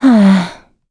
Gremory-Vox_Sigh.wav